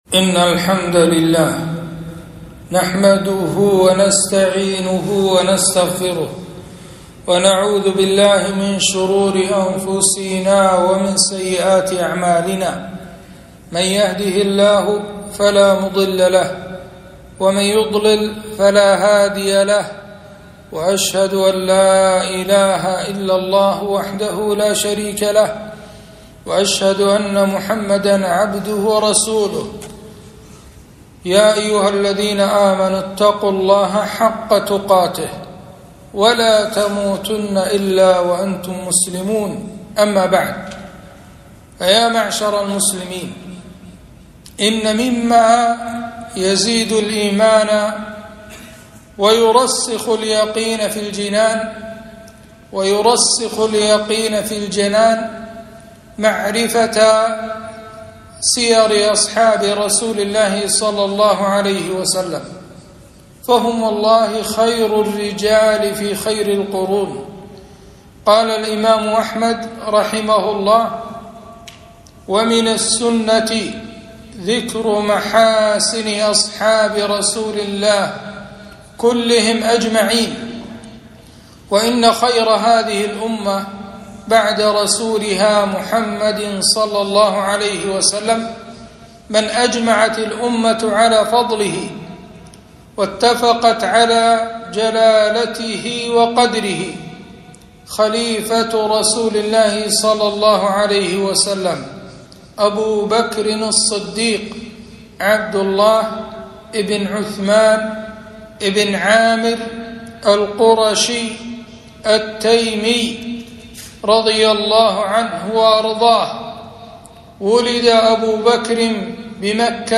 خطبة - فضائل أبي بكر الصديق رضي الله عنه